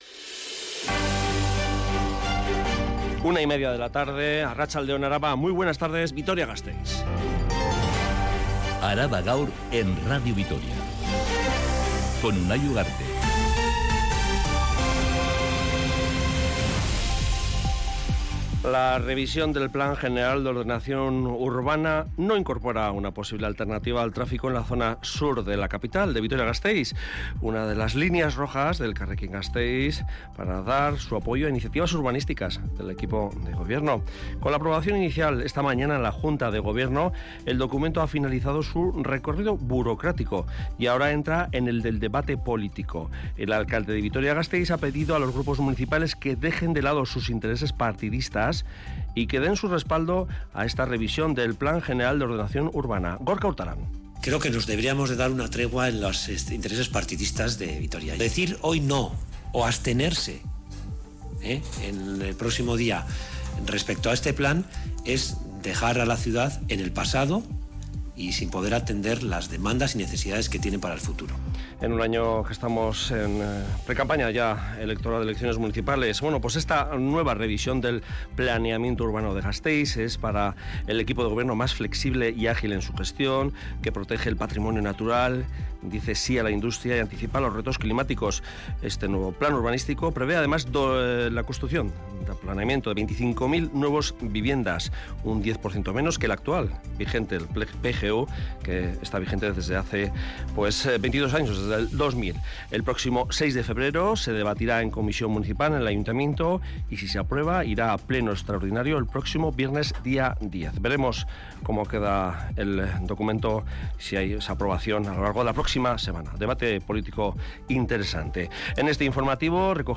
Radio Vitoria ARABA_GAUR_13H Araba Gaur (Mediodía) (27/01/2023) Publicado: 27/01/2023 14:30 (UTC+1) Última actualización: 27/01/2023 14:30 (UTC+1) Toda la información de Álava y del mundo. Este informativo que dedica especial atención a los temas más candentes de la actualidad en el territorio de Álava, detalla todos los acontecimientos que han sido noticia a lo largo de la mañana.